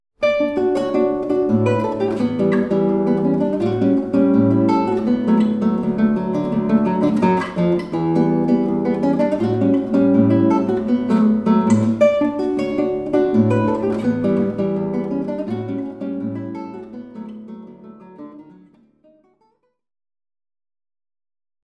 Here’s a short sample recording made by sticking an H2 in front of a guitarist. It was recorded at 320 kbps (the recording level was a bit low so the noise level is higher than it should be). The recording was then trimmed amplified and a fade out applied but no other processing, then exported as an Ogg file (Q10).